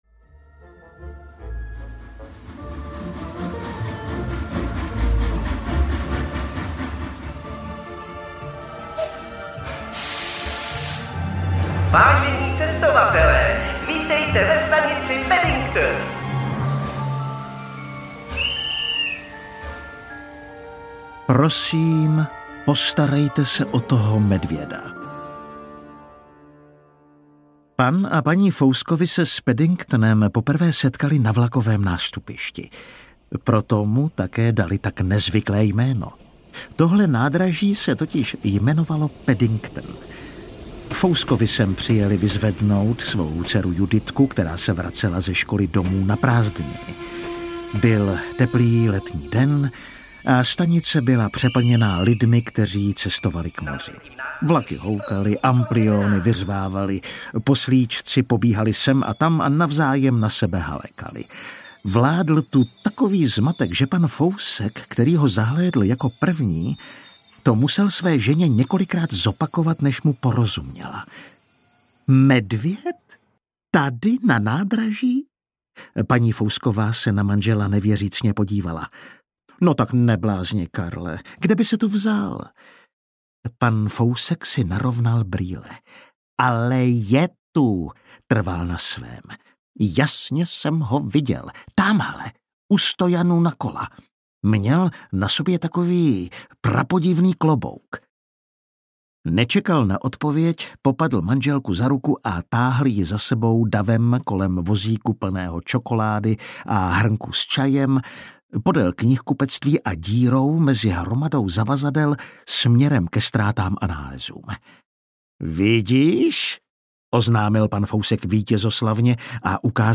Medvídek Paddington audiokniha
Ukázka z knihy